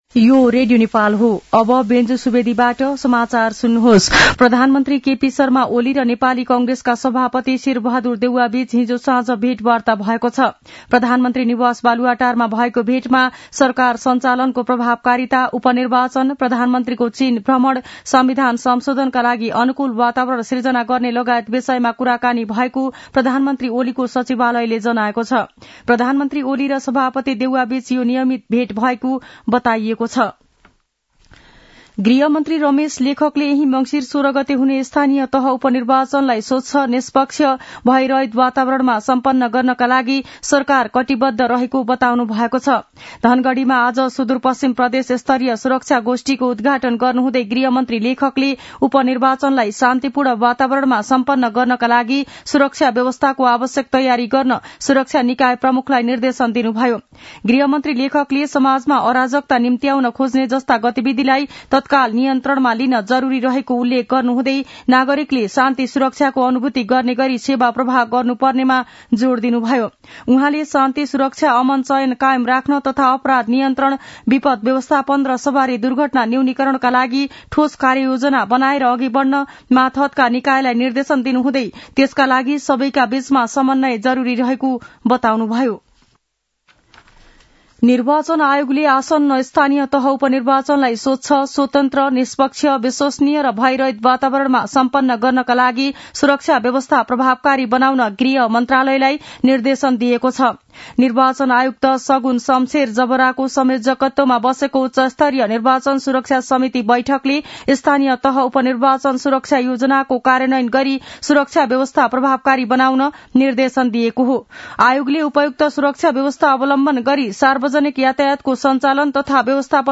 मध्यान्ह १२ बजेको नेपाली समाचार : ५ मंसिर , २०८१
12-am-nepali-news-1-3.mp3